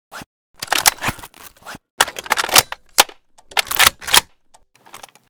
vssk_reload_empty.ogg